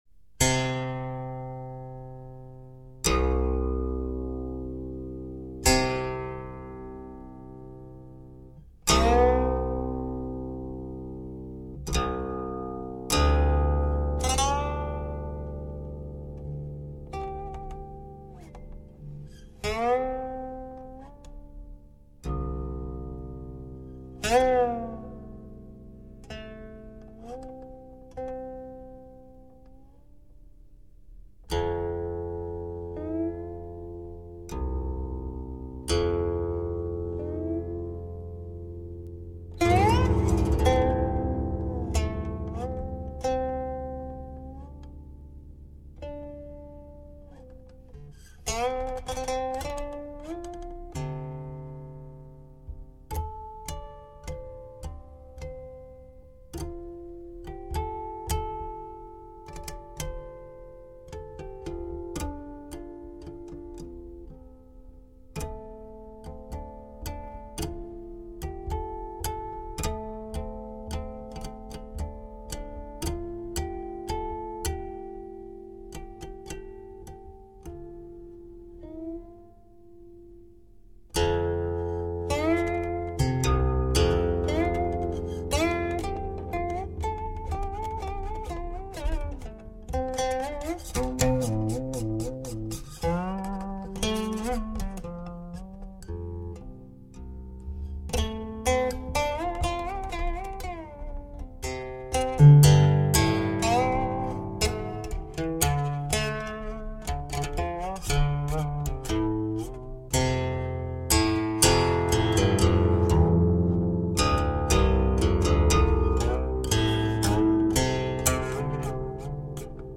该琴音色温劲松透，纯粹完美，形制浑厚古朴，优美而有气魄，高雅而不落凡尘。